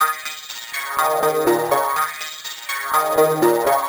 Philters Blunt Eb 123.wav